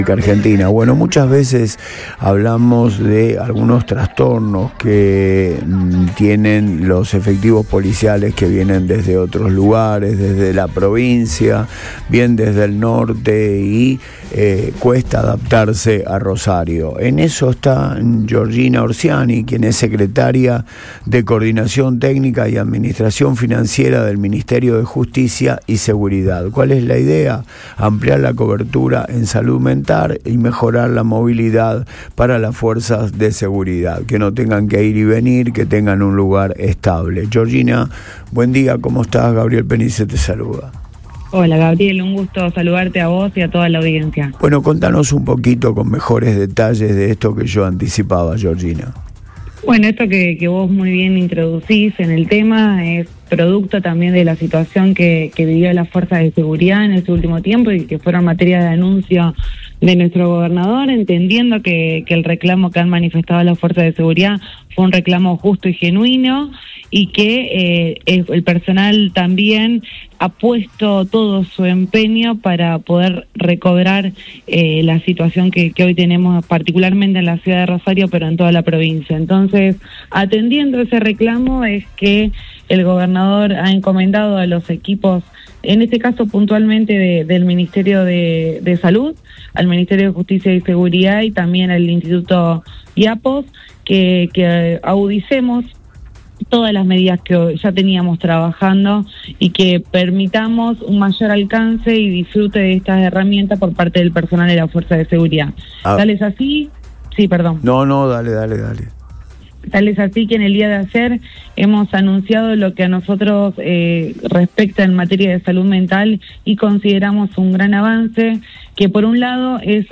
Georgina Orciani, Secretaria de Coordinación Técnica y Administración Financiera del Ministerio de Justicia y Seguridad de Santa Fe, pasó por Antes de Todo en Radio Boing para detallar los nuevos beneficios para las fuerzas de seguridad.